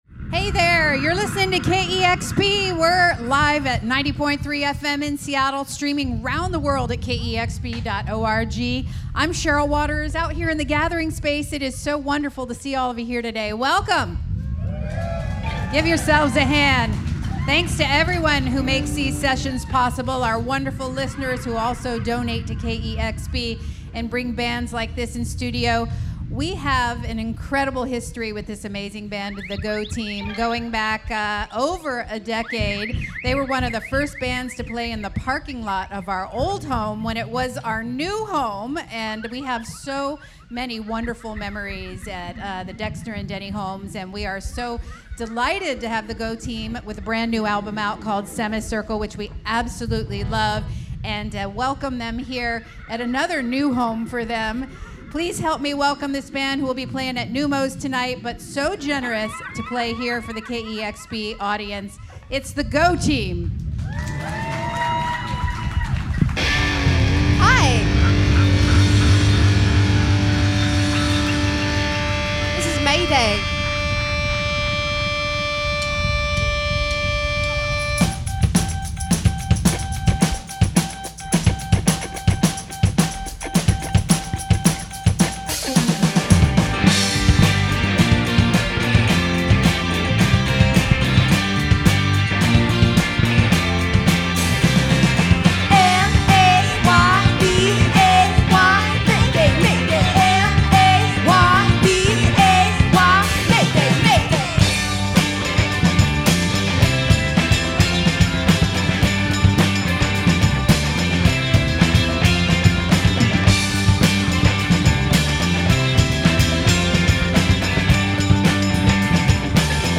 weaves hip-hop beats, vivid horns, and a kinetic energy